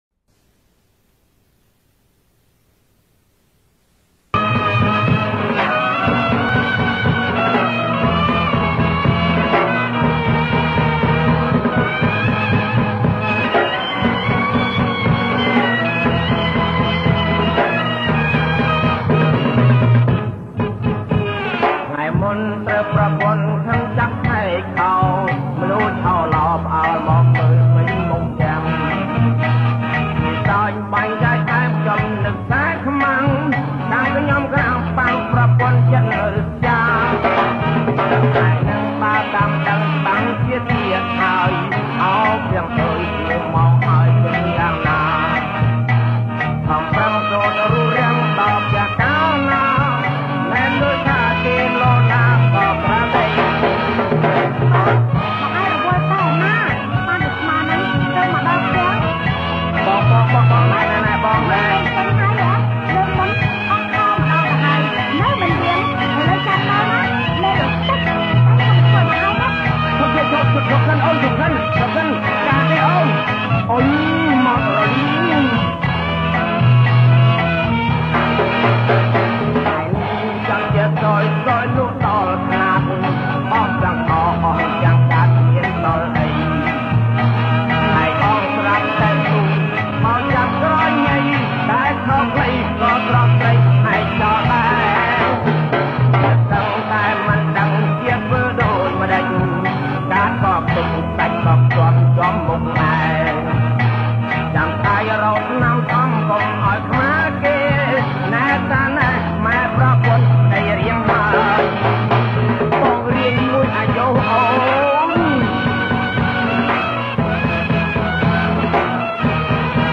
• ប្រគំជាចង្វាក់ Cha Cha Cha